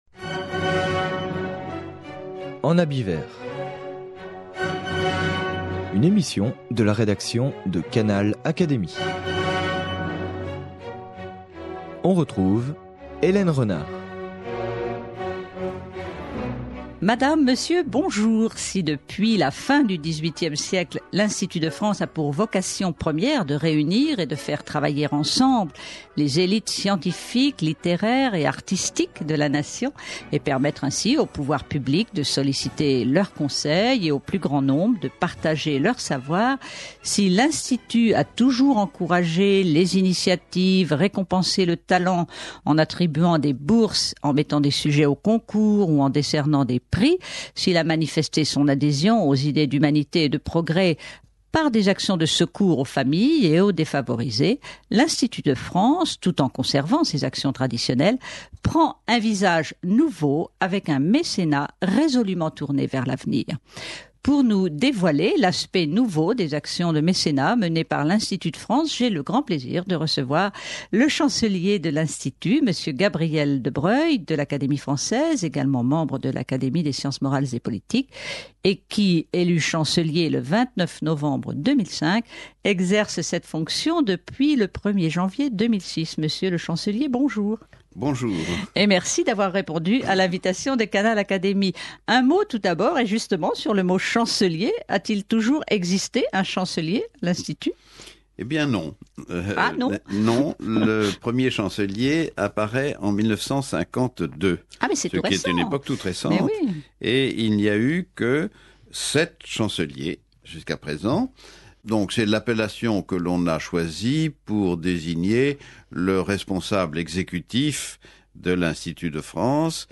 Entretien exclusif avec M. Gabriel de Broglie, de l'Académie française, de l'Académie des sciences morales et politiques, élu chancelier de l'Institut de France depuis le 1er janvier 2006.